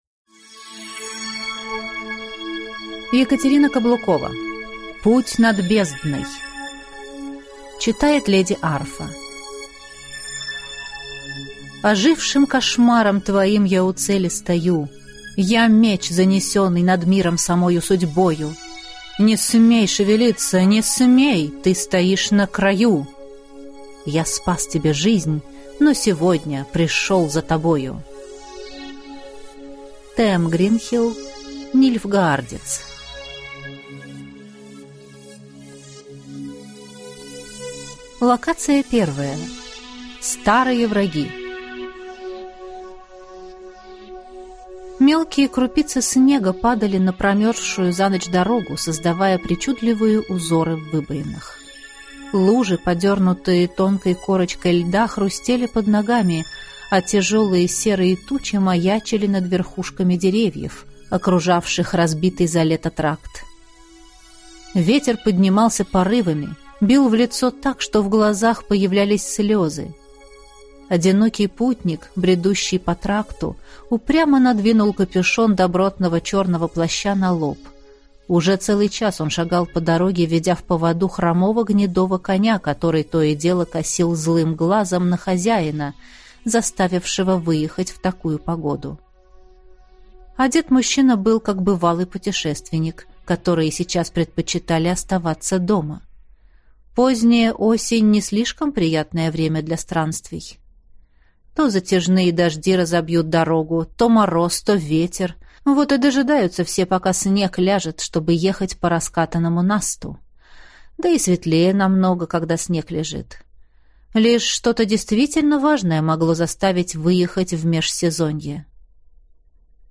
Аудиокнига Путь над бездной | Библиотека аудиокниг